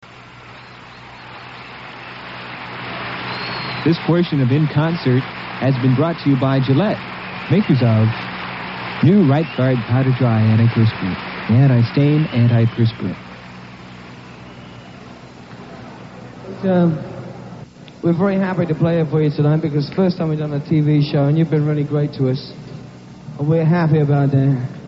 Venue: Hofstra University
Byron Quote